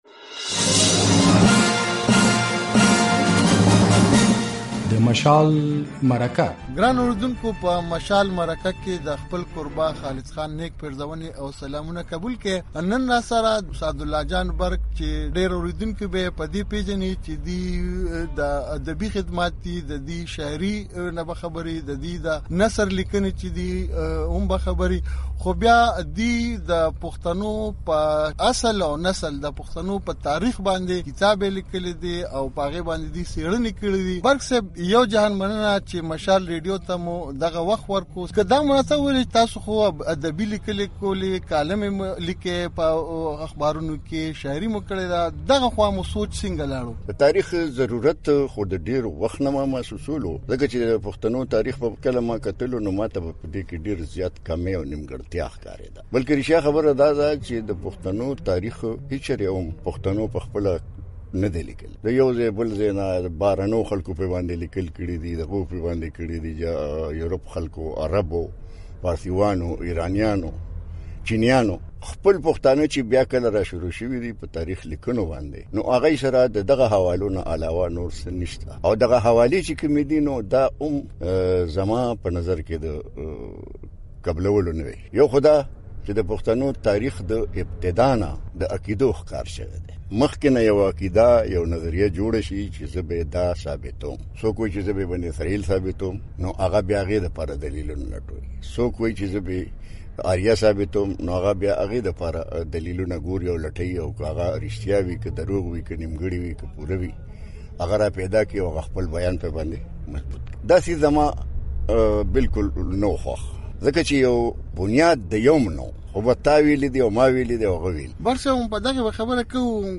تفصیلي مرکه